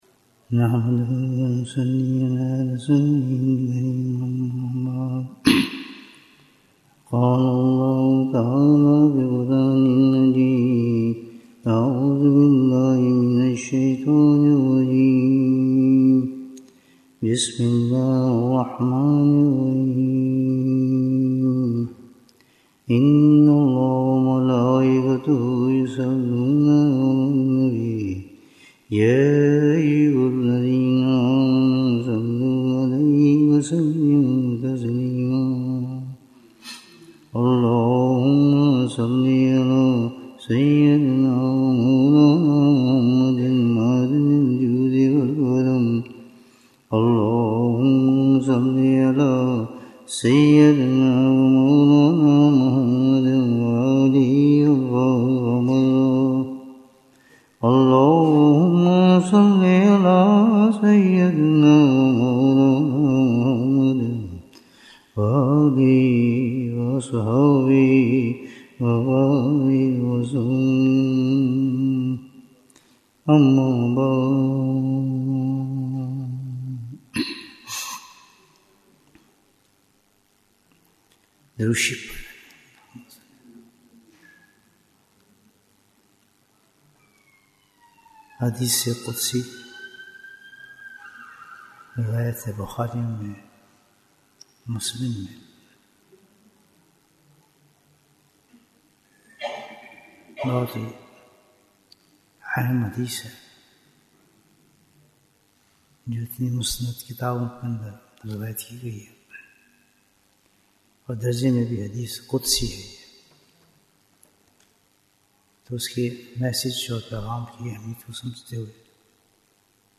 Bayan, 47 minutes 20th March, 2025 Click for English Download Audio Comments Bayan Episode 27 - How Can We Obtain Pure Deen?